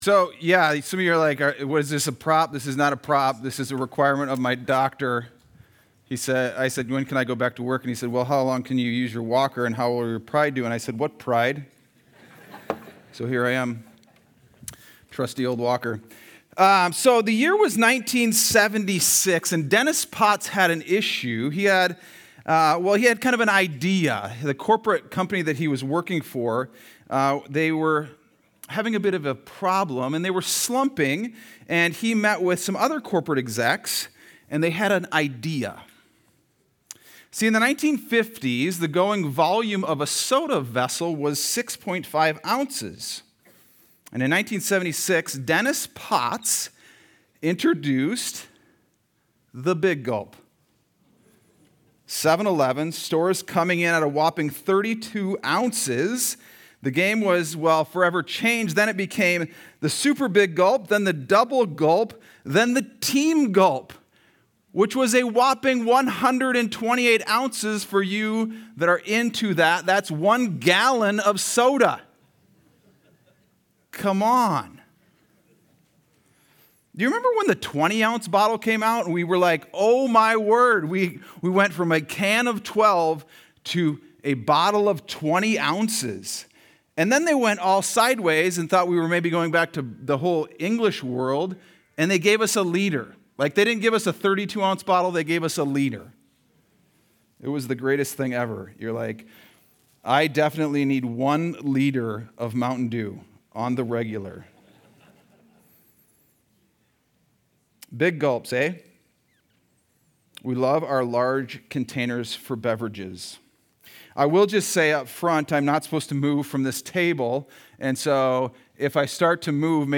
Sunday Sermon: 10-12-25